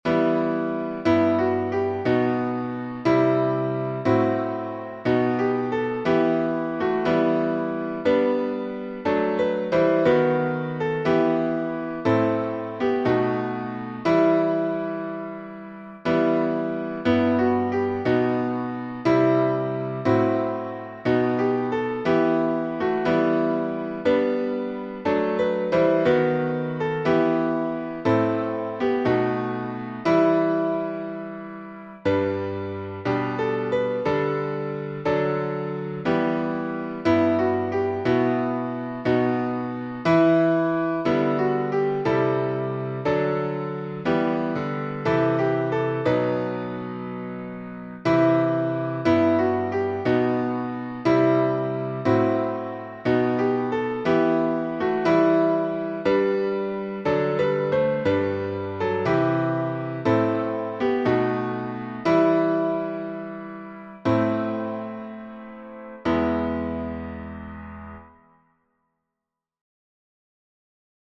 Words by Samuel Trevor FrancisTune: EBENEZER by Thomas J. Williams (1869-1944)Key signature: G major (1 sharp)Time signature: 4/2Meter: 8.7.8.7.D.Public Domain1.